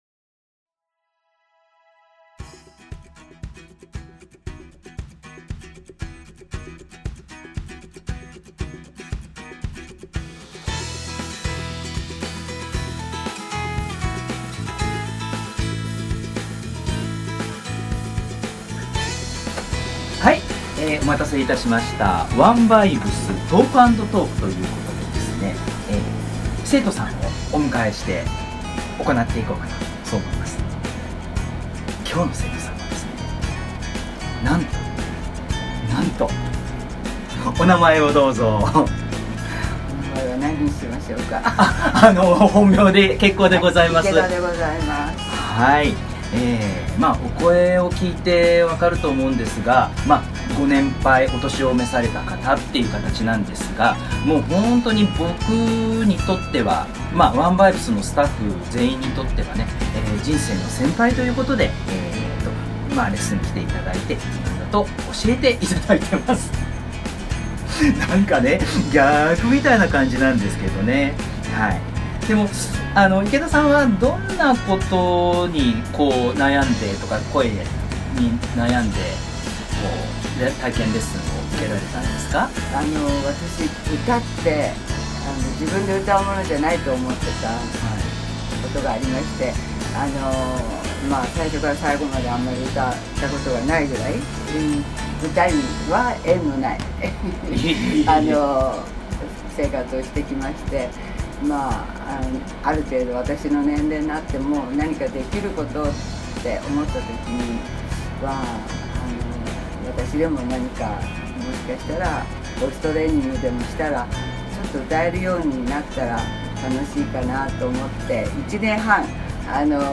第2回目は、いよいよ生徒さんとの対談です。
最後まで音声対談とお聴きになれば出てきます。